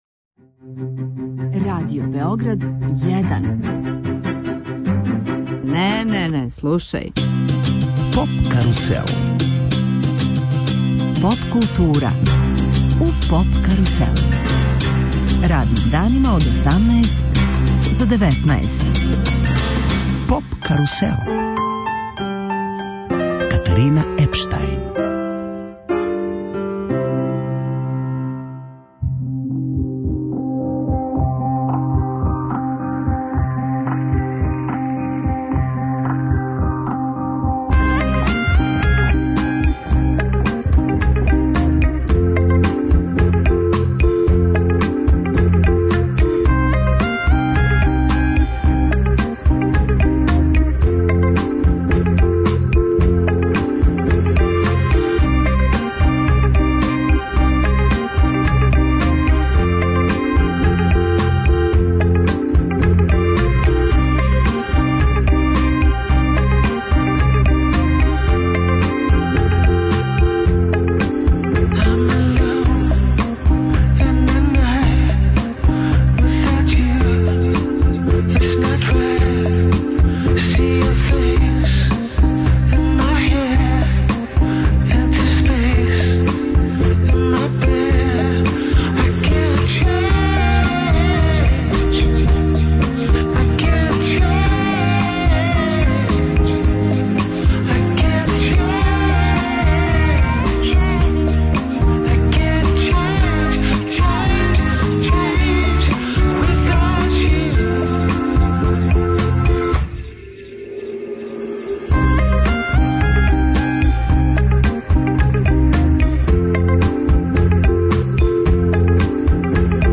Гости емисије биће и чланови састава Smoke'n'Soul, који су управо објавили албум 'Soul Infected'.